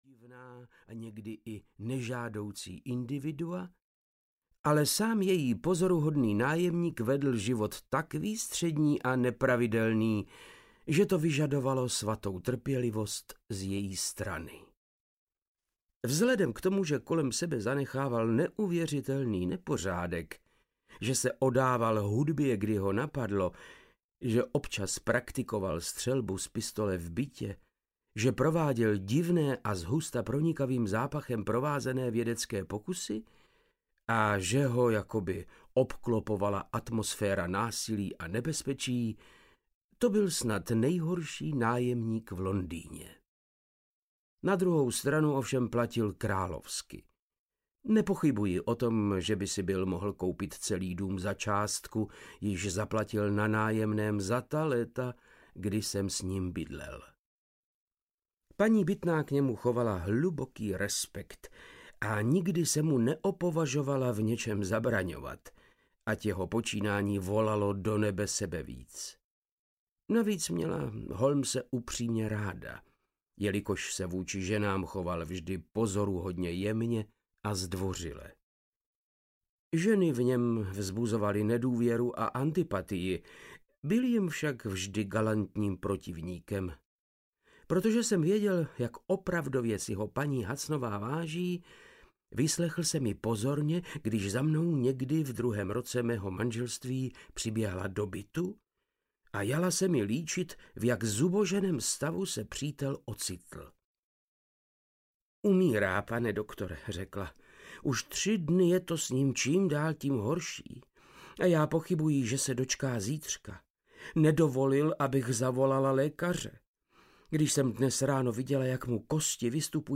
Umírající detektiv audiokniha
Ukázka z knihy
• InterpretVáclav Knop